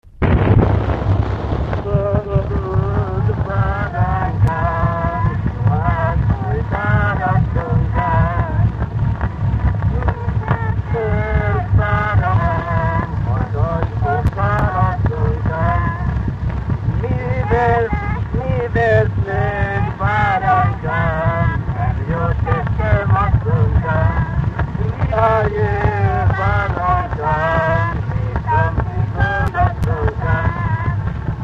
Alföld - Csongrád vm. - Szegvár
Gyűjtő: Vikár Béla
Stílus: 7. Régies kisambitusú dallamok
Szótagszám: 6.6.7.7